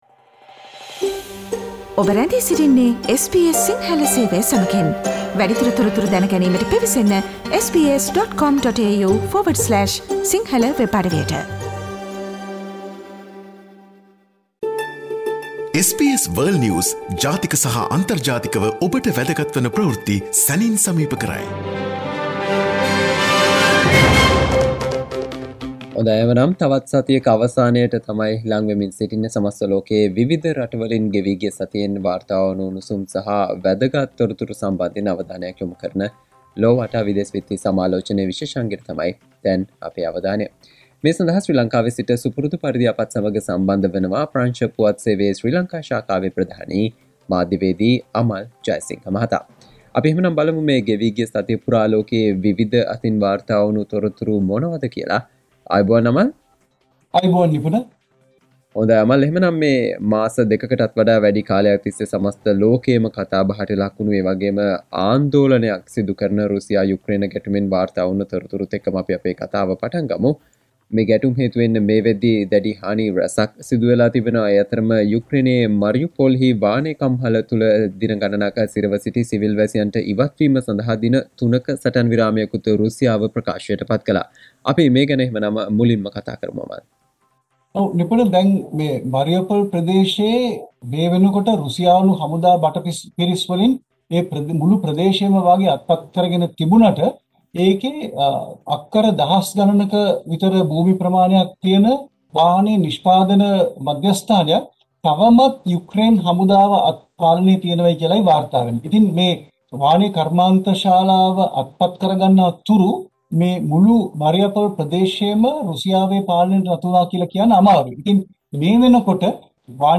AFP Journalist and the world news critic World's prominent news highlights in a few minutes - listen to SBS Si Sinhala Radio's weekly world News wrap on Friday Share